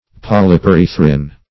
Search Result for " polyperythrin" : The Collaborative International Dictionary of English v.0.48: Polyperythrin \Pol`y*pe*ryth"rin\, n. [Polyp + Gr.